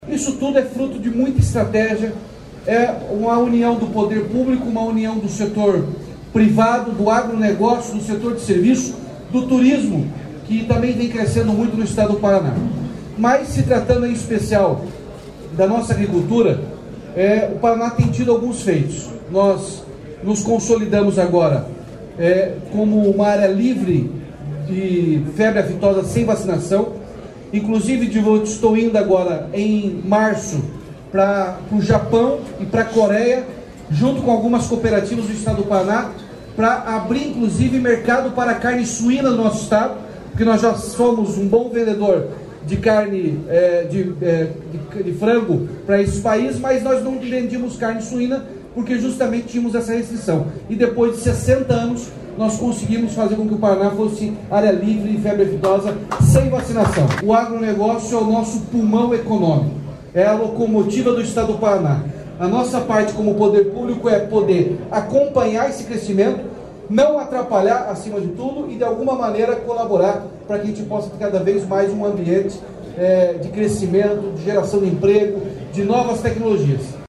Sonora do governador Ratinho Junior sobre investimentos do governo com o setor privado no agronegócio, em fala no Show Rural, em Cascavel